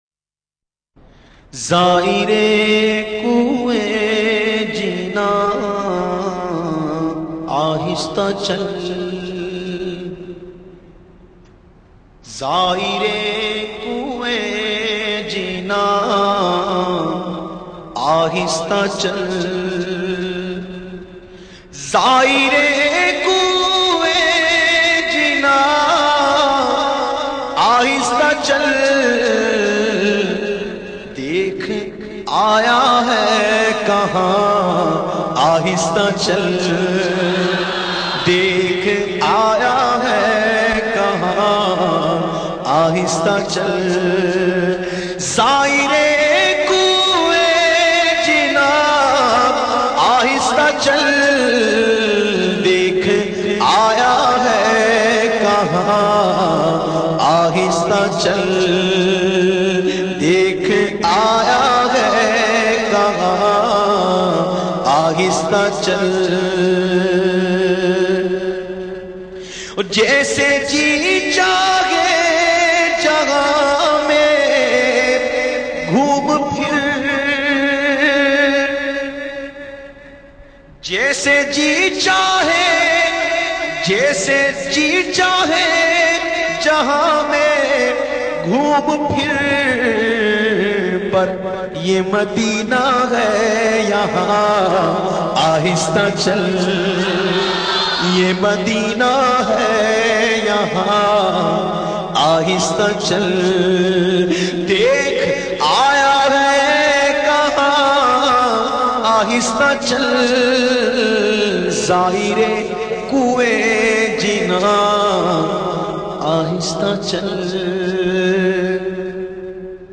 Naat Sharif